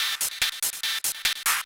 K-6 Loop 1.wav